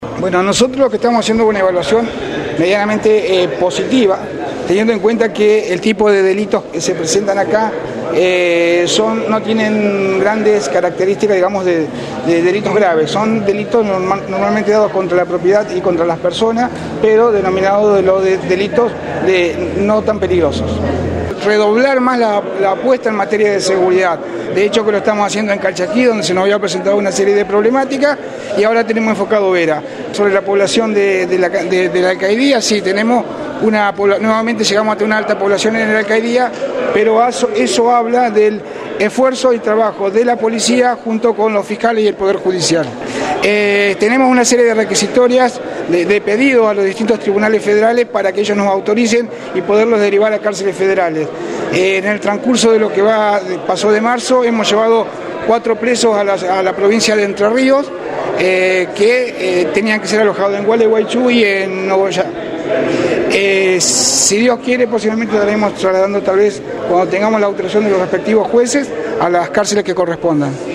AUDIO: el Jefe de la Unidad Regional, Marcelo Bustamante, se refirió al balance que hace sobre estos meses en la gestión, a los hechos de inseguridad en Calchaquí y a la problemática que trae para el sector de alcaidía la cantidad de presos federales.